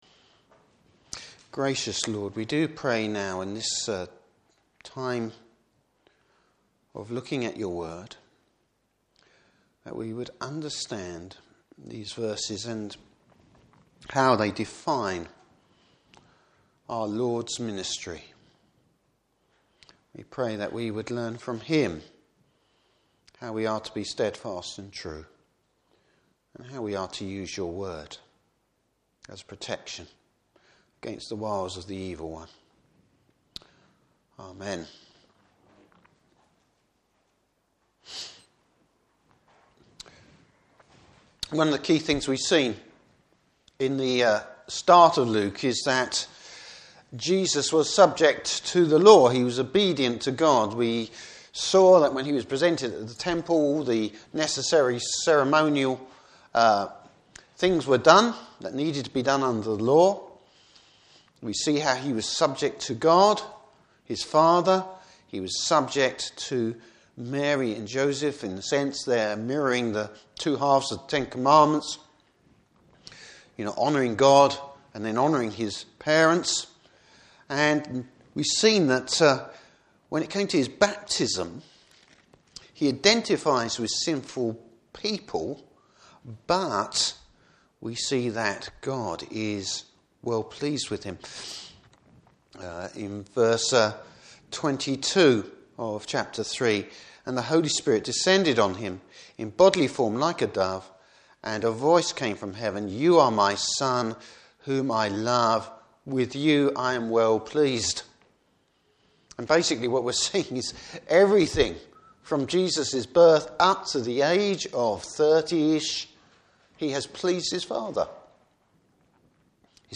Service Type: Morning Service Bible Text: Luke 4:1-13.